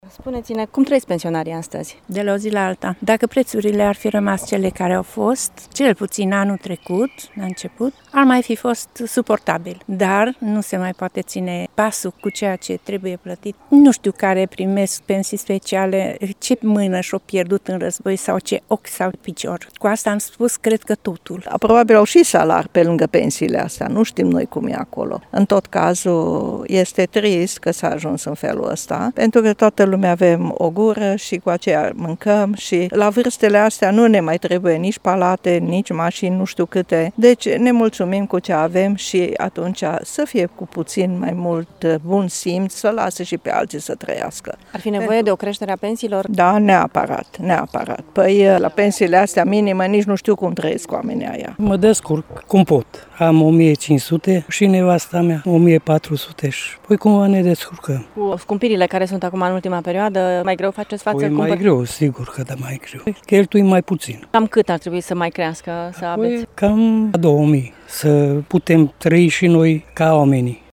Mulți dintre târgumureșeni trăiesc cu pensia minimă și spun că, odată cu scumpirile din acest an, fac din ce în ce mai greu față vieții de zi cu zi: